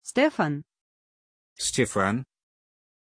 Aussprache von Stefan
pronunciation-stefan-ru.mp3